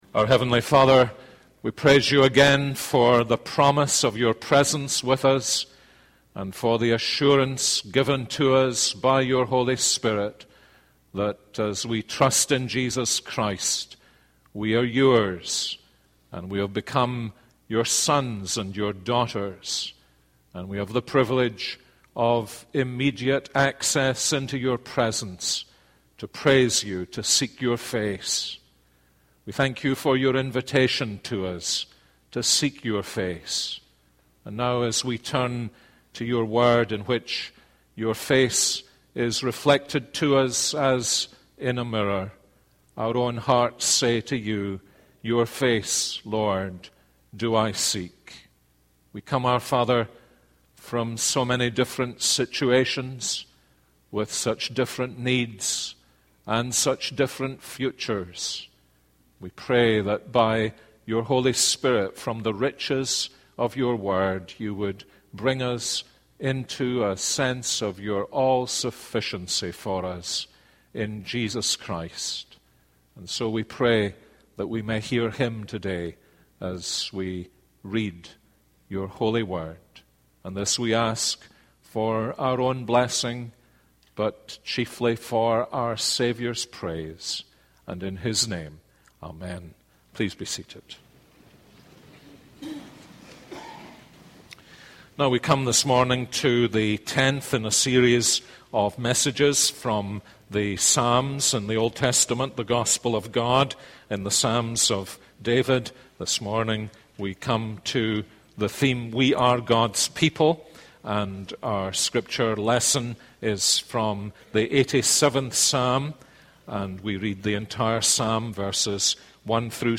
This is a sermon on Psalm 87:1-7.